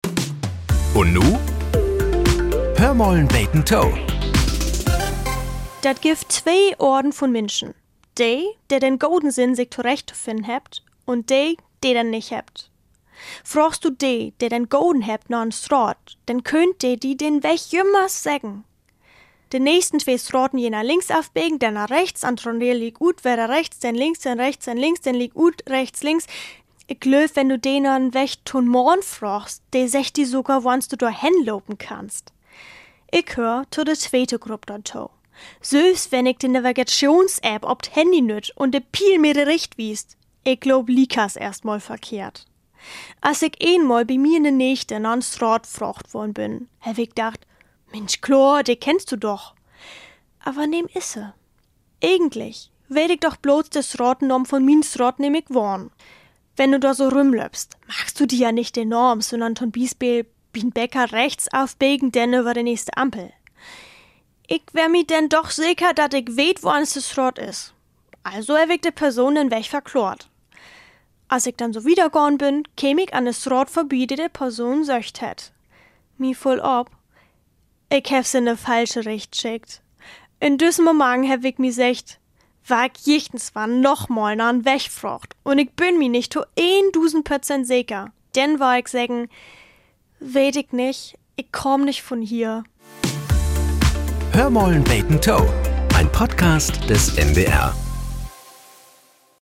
Nachrichten - 15.01.2025